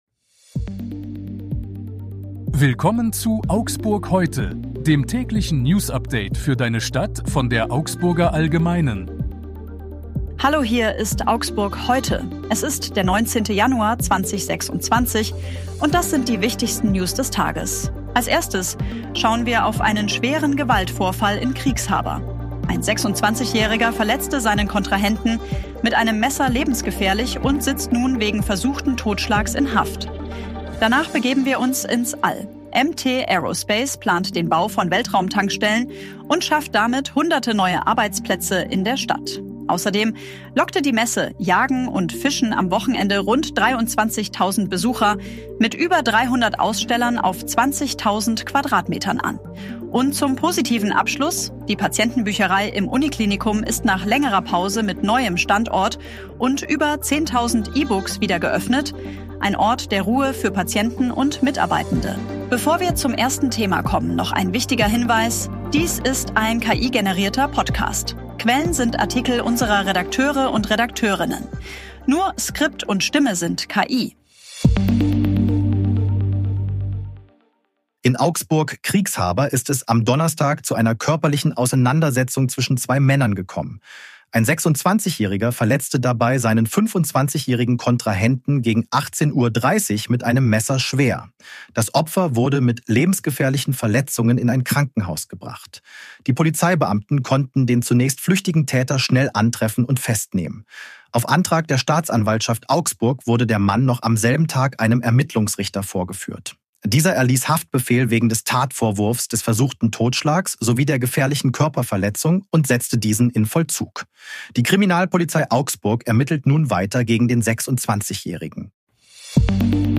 Die aktuellen Nachrichten aus Augsburg vom 19. Januar 2026.
Stimme sind KI.